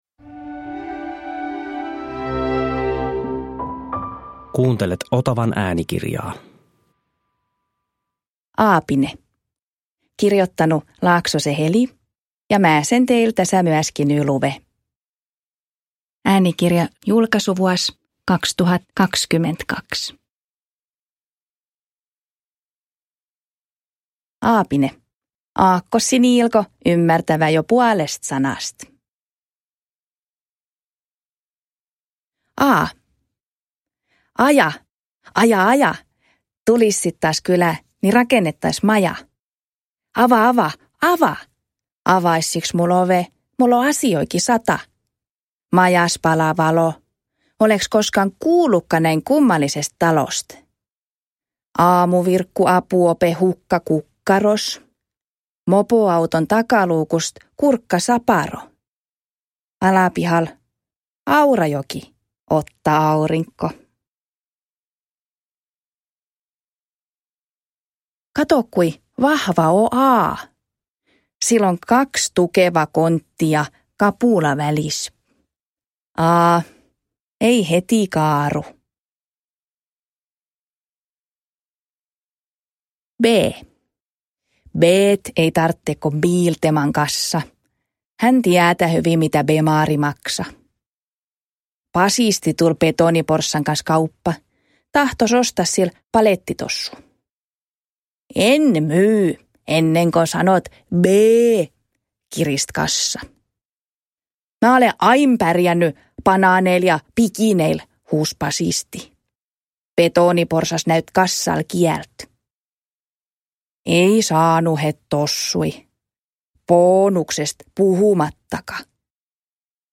Aapine – Ljudbok – Laddas ner
Uppläsare: Heli Laaksonen